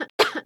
Add cough sound effects
cough_w_2.ogg